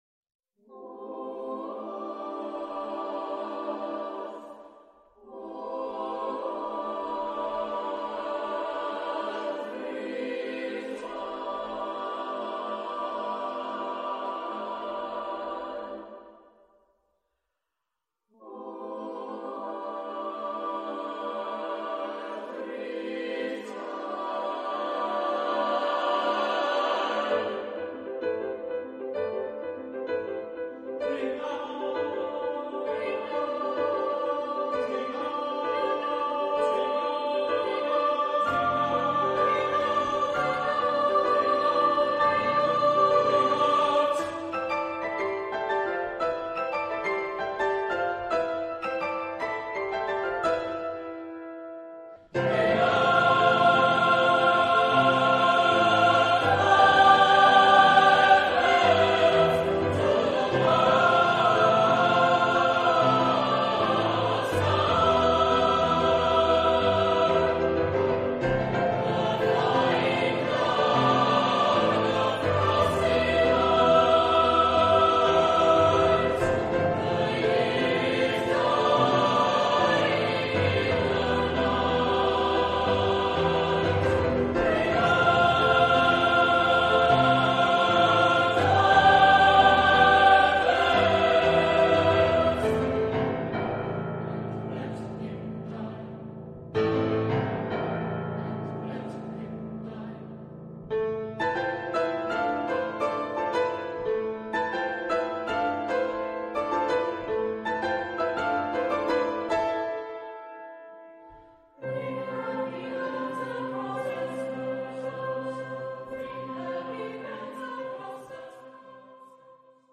Epoque: 20th century
Genre-Style-Form: Secular ; Choir
Mood of the piece: contrasted
Instrumentation: Piano  (1 instrumental part(s))
Tonality: A minor